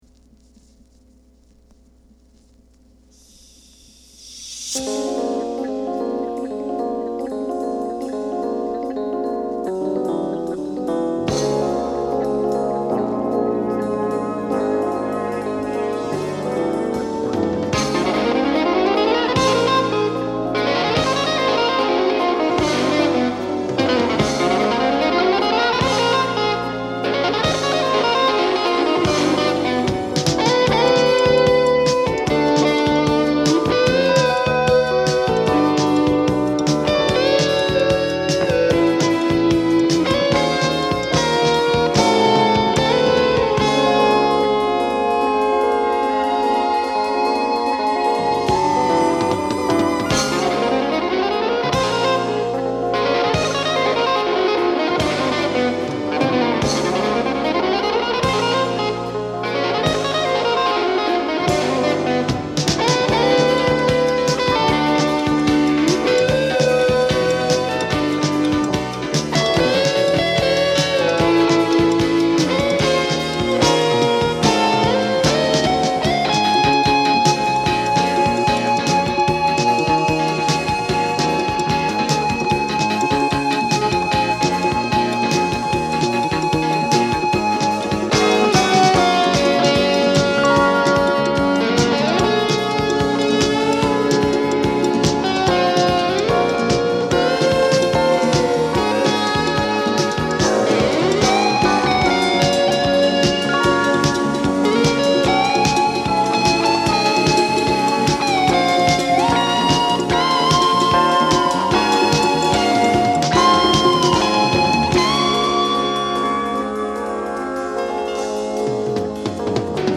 Genre: Jazz Fusion / Jazz Rock
ギター・シンセサイザーも多用し、タイトル曲をはじめロック色とフュージョンの質感が全編に混在する。
鋭いギターのトーンとバンドのグルーヴが一体となって流れる5分24秒。